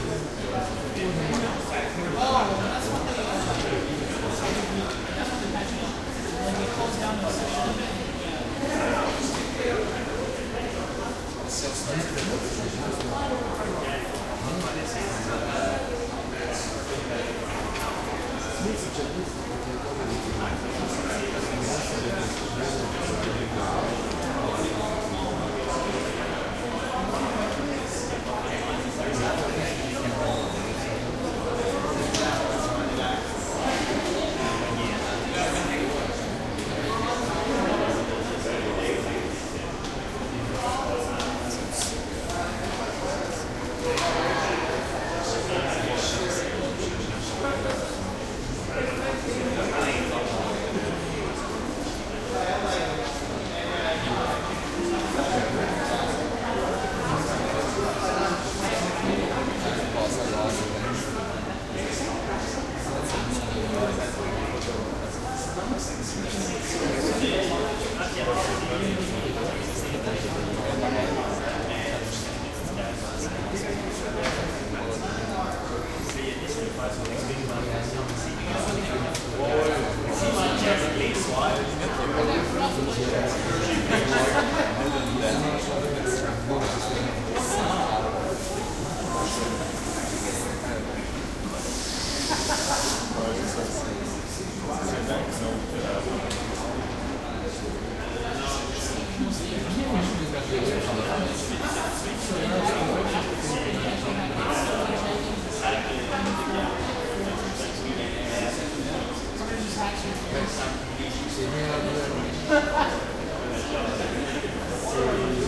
schoolLoop.ogg